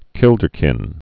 (kĭldər-kĭn)